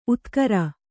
♪ utkara